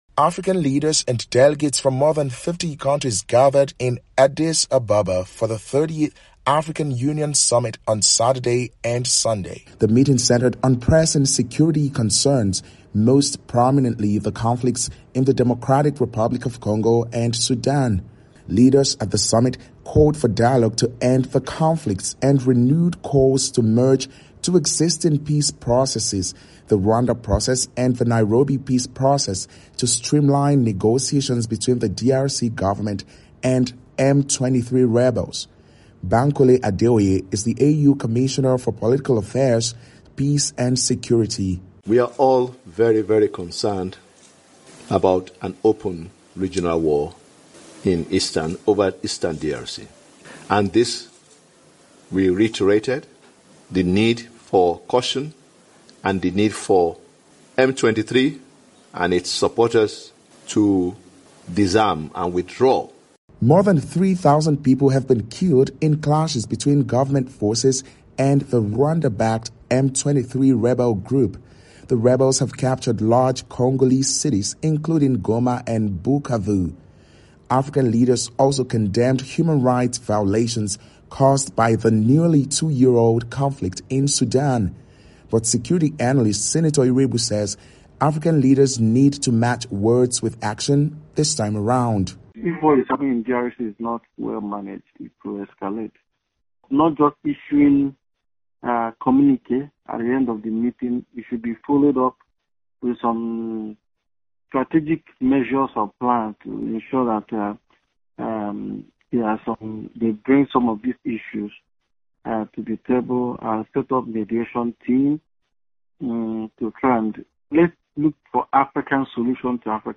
reports from Abuja.